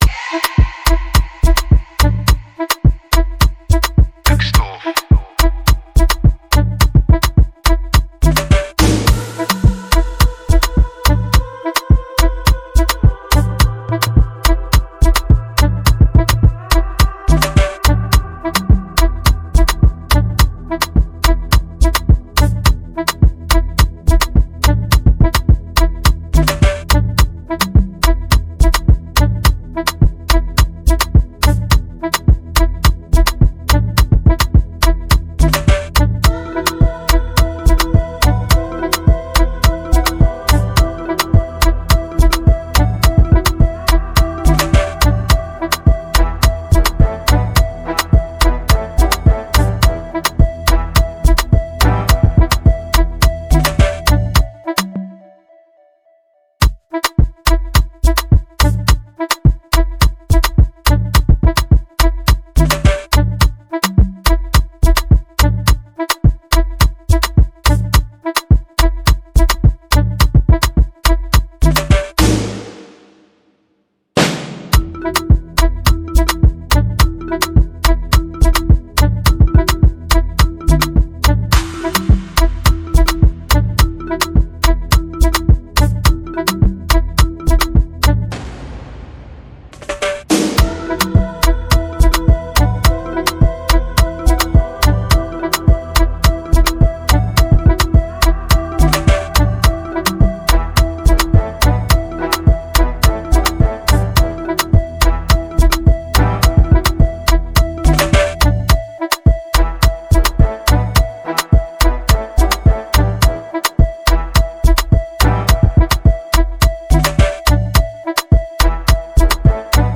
GENRE: Afrobeat